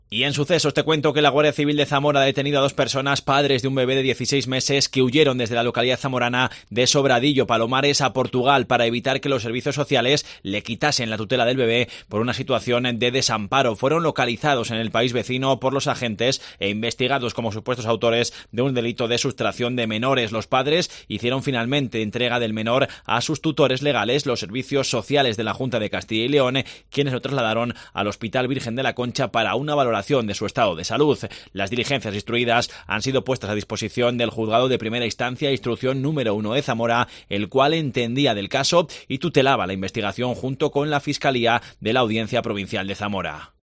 Crónica sustracción de menores Zamora